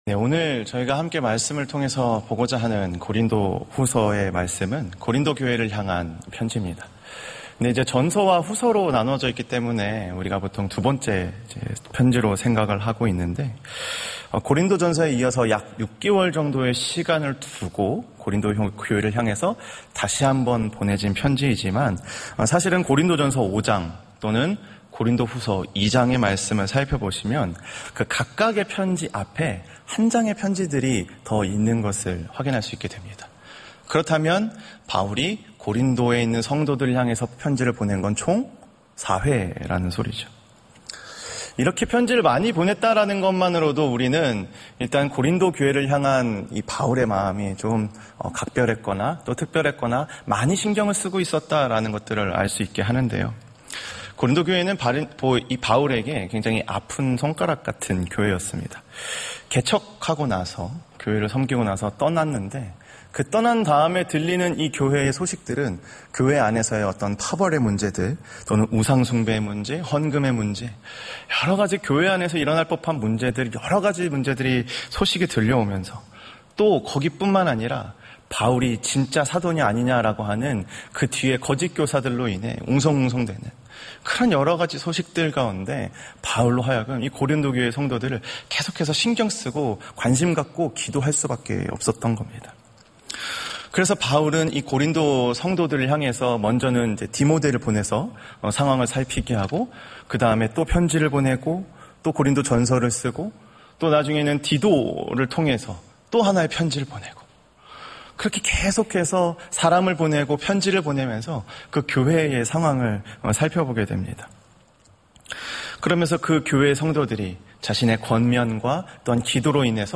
수요예배